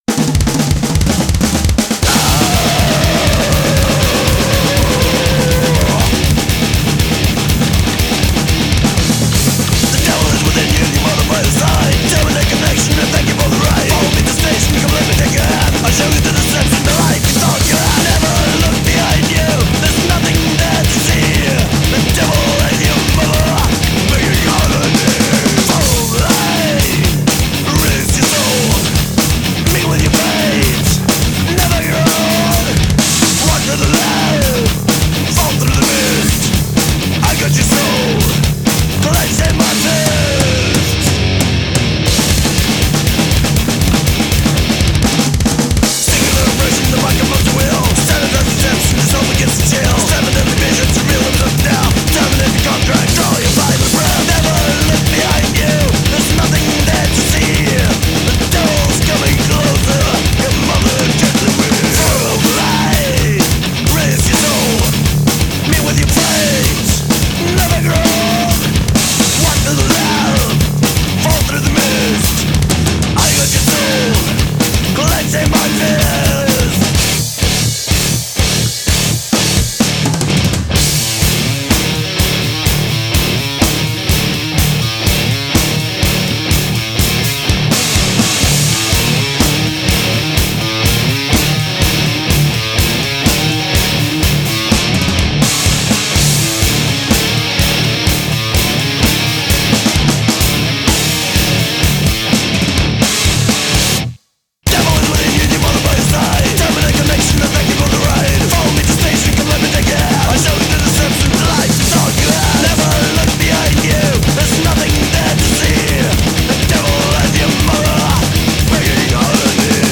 Recorded January 2004 at Skansen Lydstudio, Trondheim.